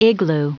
igloo ["Iglu:]